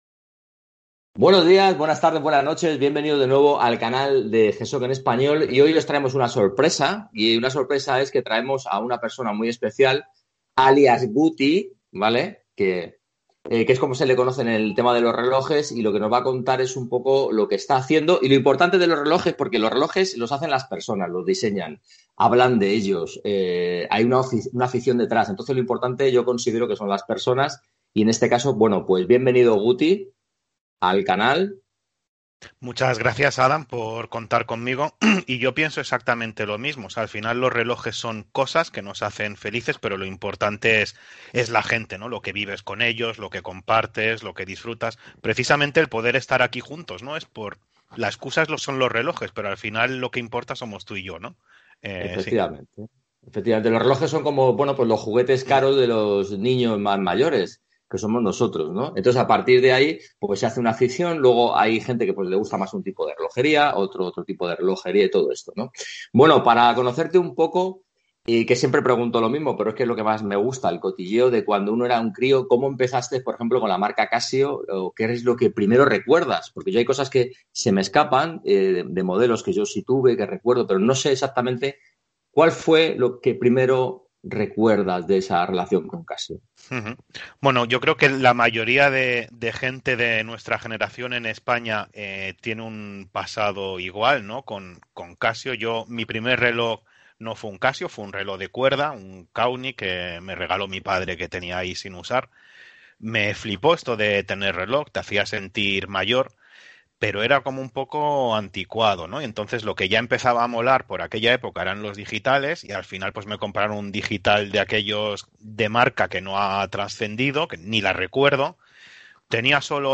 entrevista_ges.mp3